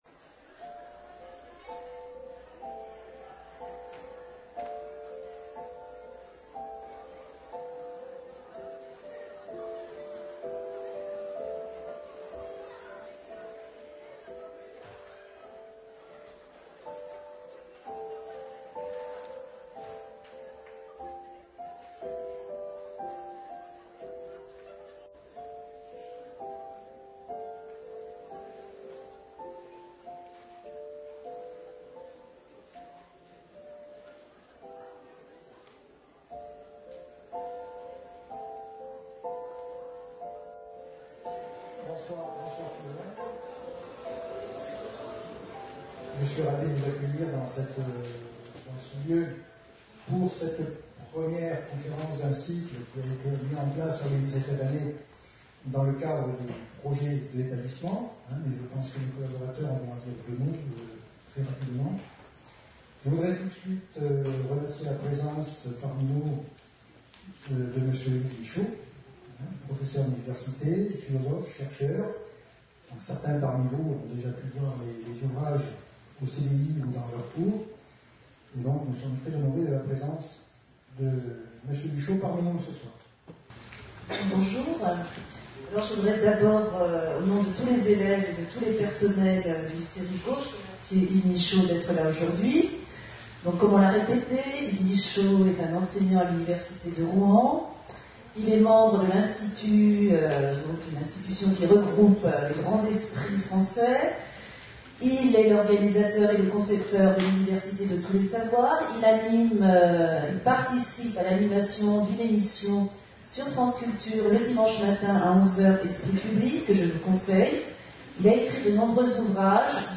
Une conférence de l'UTLS au lycée Lycée Rive Gauche (31 Toulouse)Avec Yves Michaud (philosophe)Partenariat avec la mission agrobiosciences - Université des Lycéens